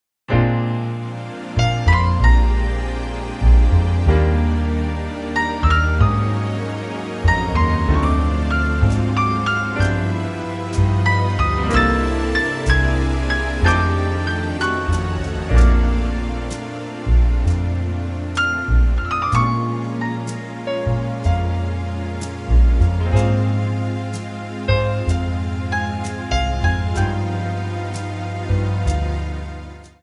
Bb/Db
MPEG 1 Layer 3 (Stereo)
Backing track Karaoke
Pop, Musical/Film/TV, 1980s